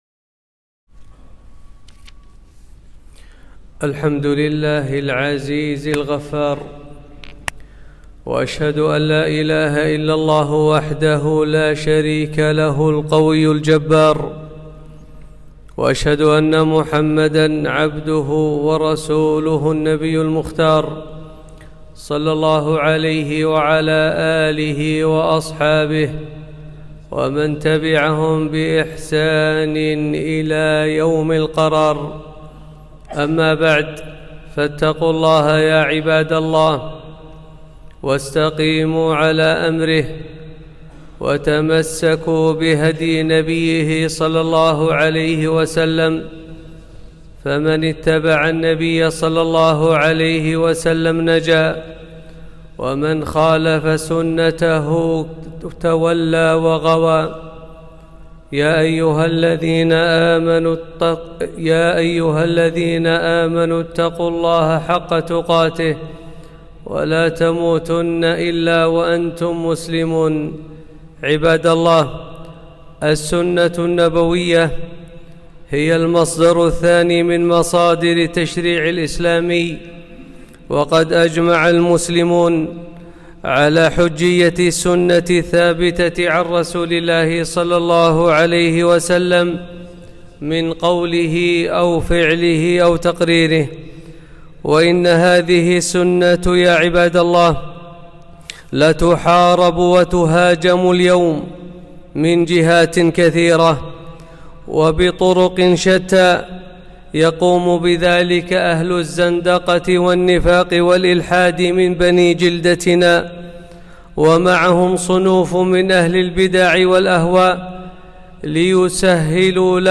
خطبة - تعظيم السنه والرد على الملحدين